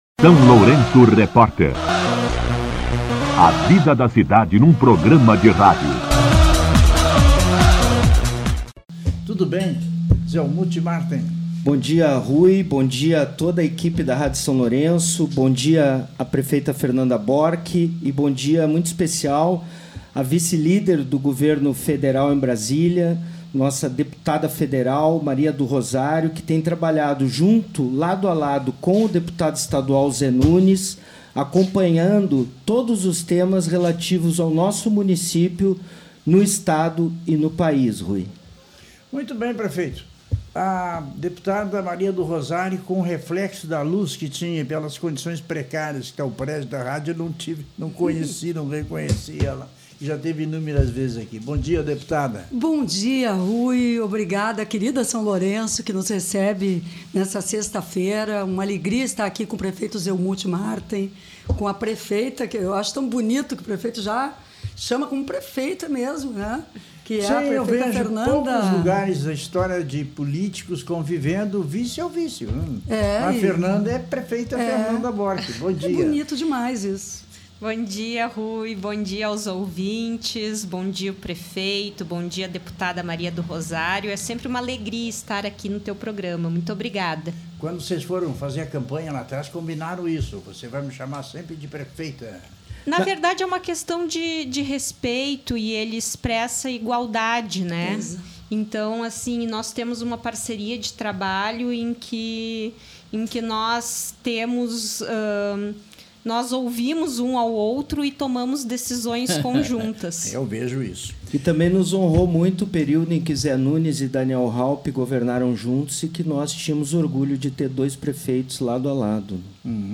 Entrevista com A deputada federal Maria do Rosário, prefeito Zelmute Marten e da vice-prefeita Fernanda Bork
A deputada federal Maria do Rosário, acompanhada do prefeito Zelmute Marten e da vice-prefeita Fernanda Bork, esteve nesta quinta-feira (5) no SLR RÁDIO para anunciar o encaminhamento de R$ 955 mil destinados às obras no Centro de Convenções.